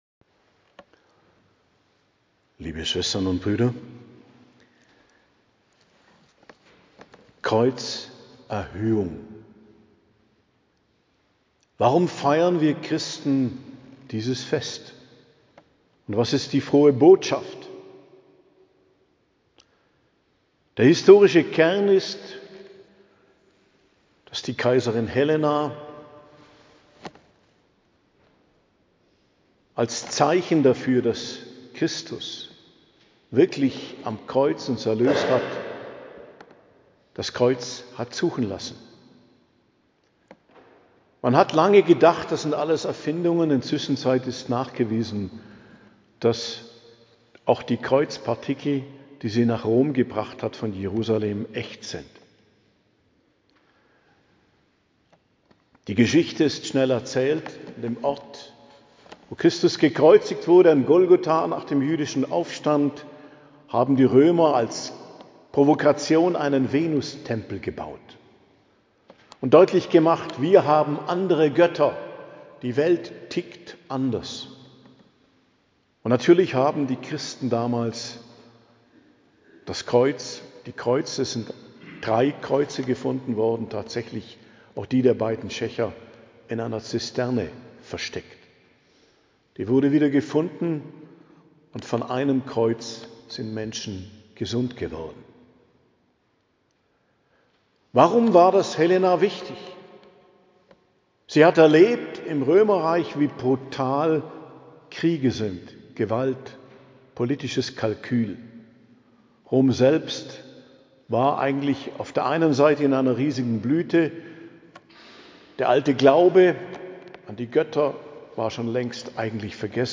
Predigt zum Fest Kreuzerhöhung, 14.09.2025 ~ Geistliches Zentrum Kloster Heiligkreuztal Podcast